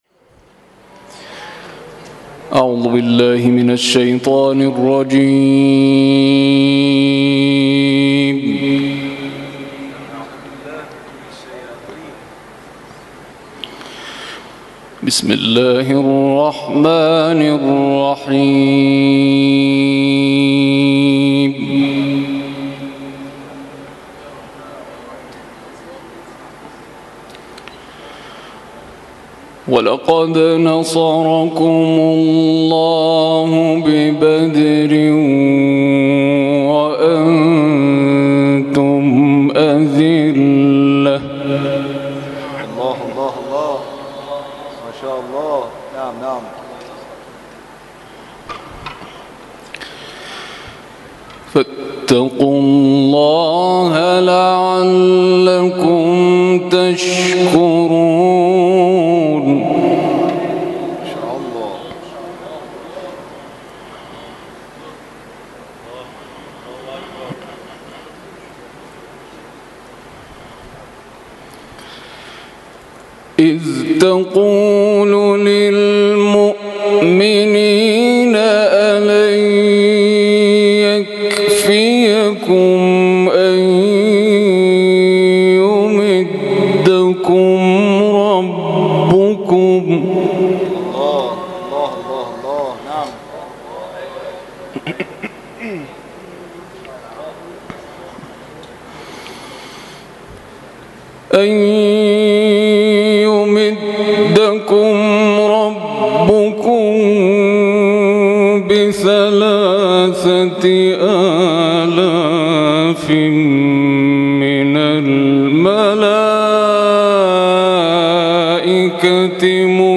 جدیدترین تلاوت
با حضور قاریان ممتاز شهرری و قاریان مهمان در مسجد جامع‌المهدی(عج) واقع در فلکه اول دولت‌آباد برگزار شد.